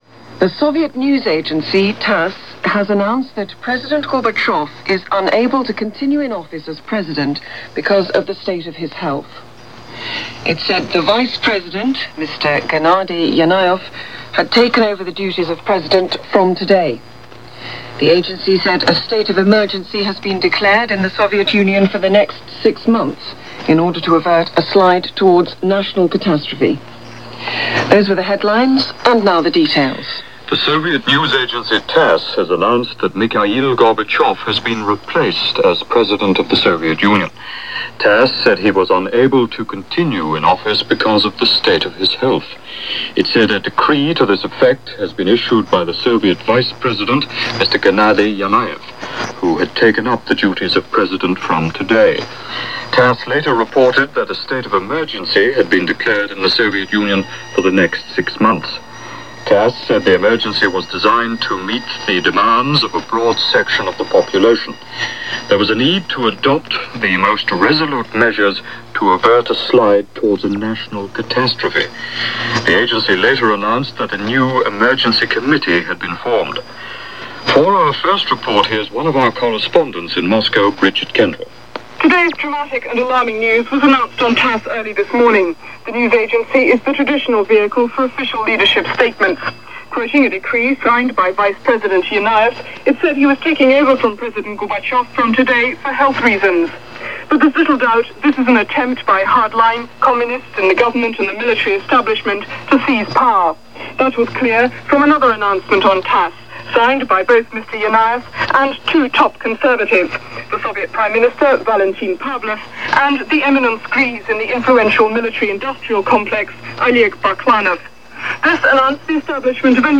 From roughly 6 in the morning on the 19th, the only news of the coup was via the BBC World Service – here is an excerpt of the goings-on from that day and the nervous days that followed.
BBC-World-Service-Moscow-August-1991.mp3